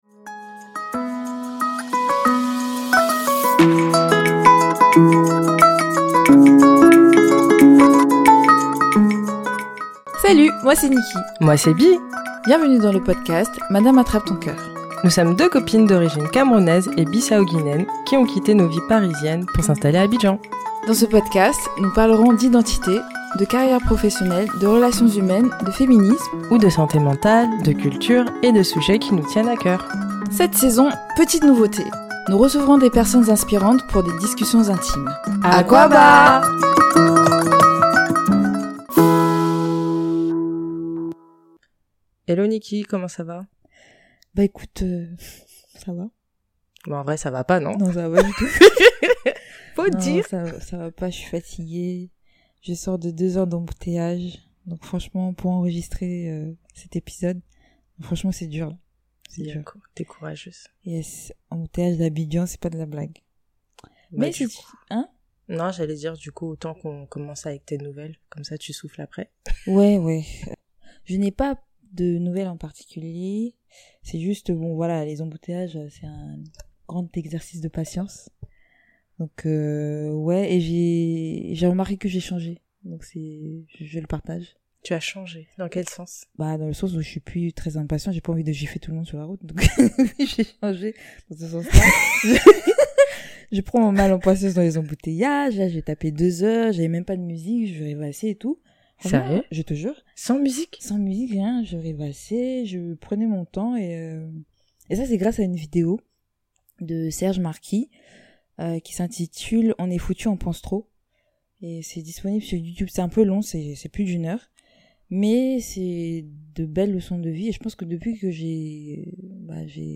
la kora